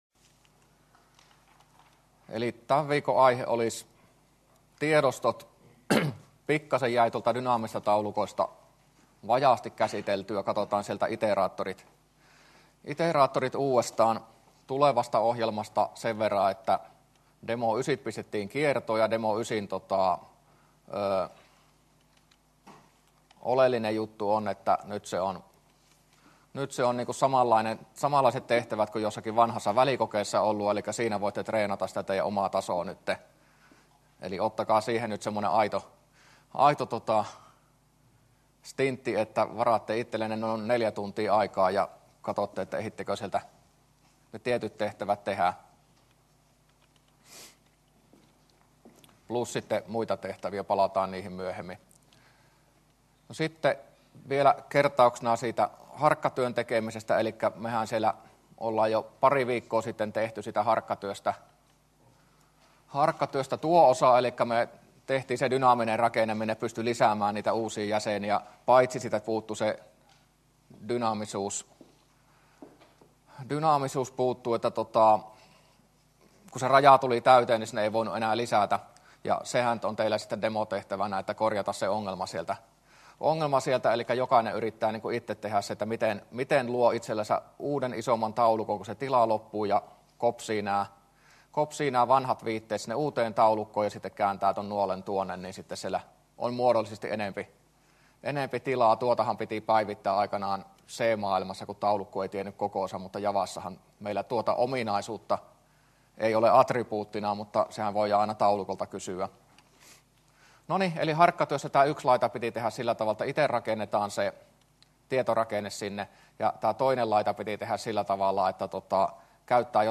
luento17a